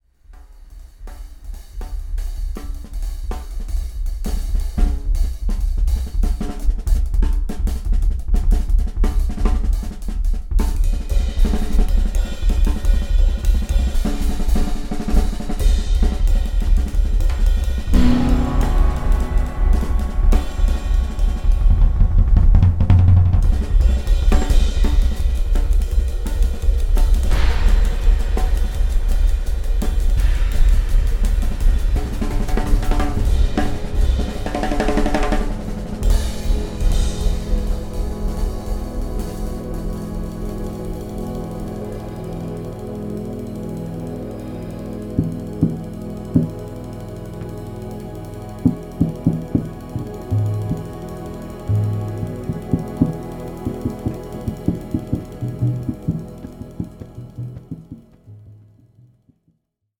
ホーム ｜ JAZZ
ハープとモジュラー・シンセが奏でる瞑想的なサウンド